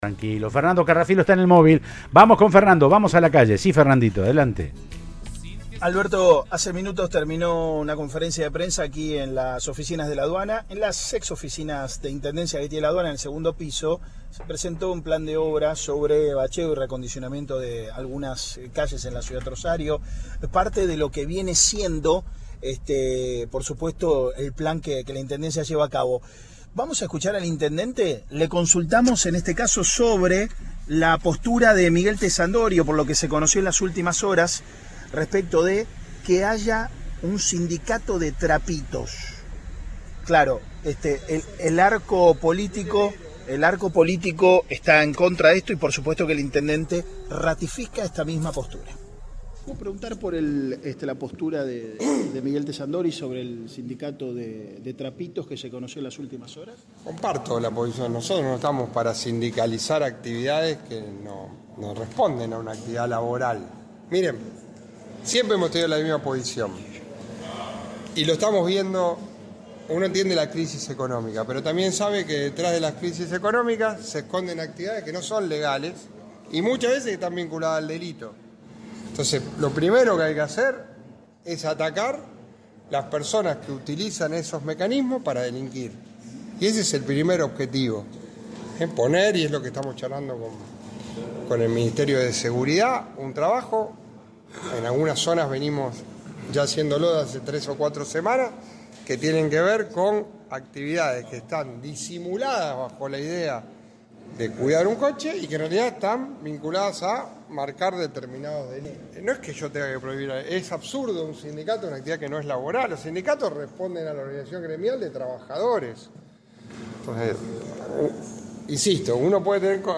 En ese marco, Javkin dijo al móvil de Cadena 3 Rosario que “no estamos para sindicalizar actividades que no responden a una actividad laboral”.